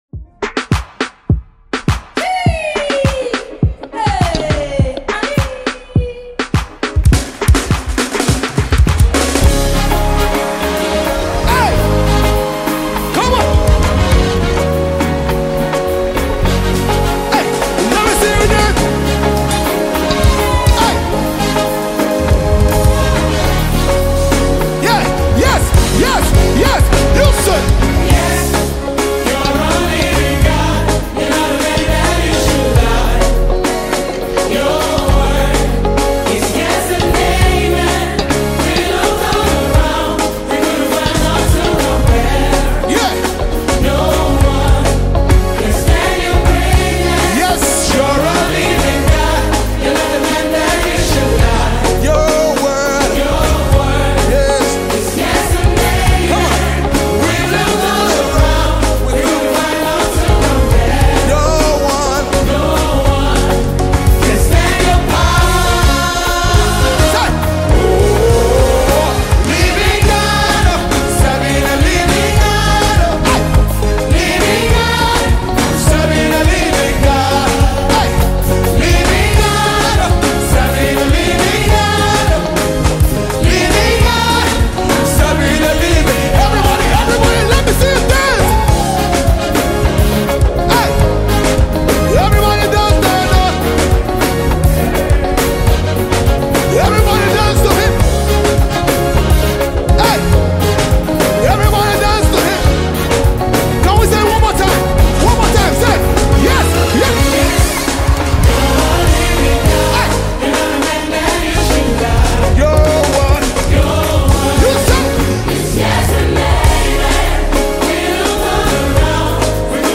worship project